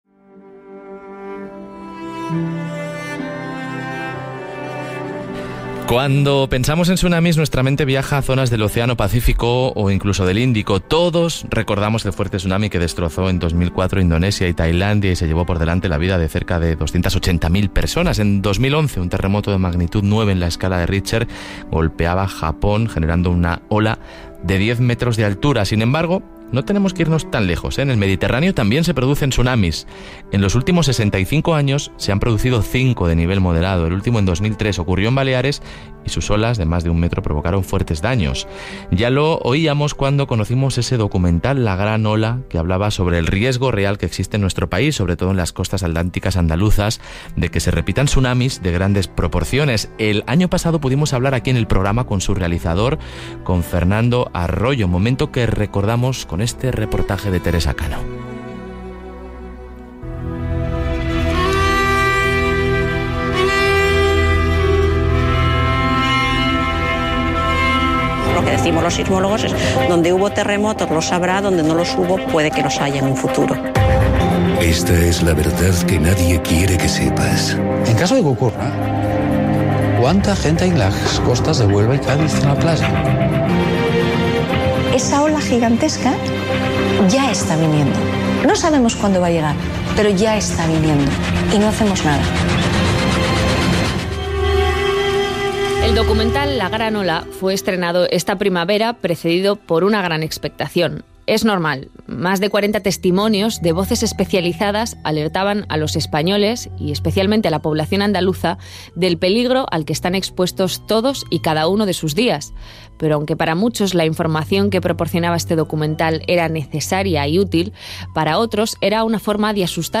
EntrevistaRadio Aragón - Programa la Cadiera